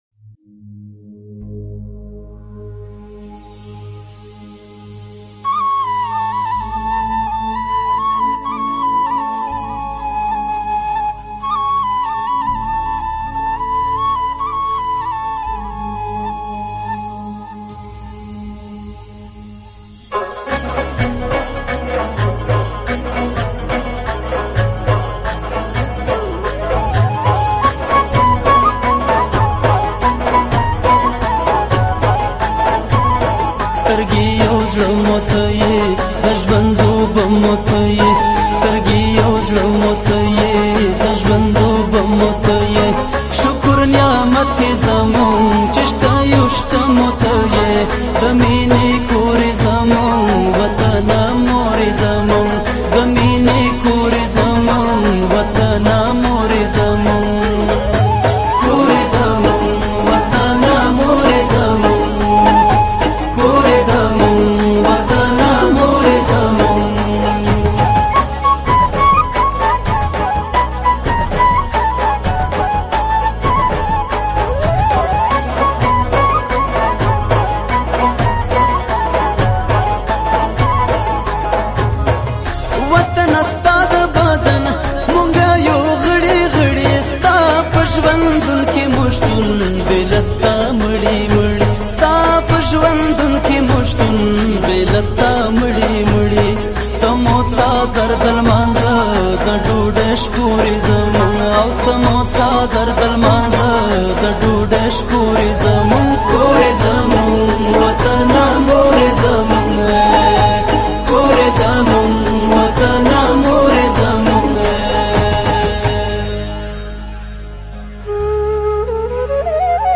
ملي سندره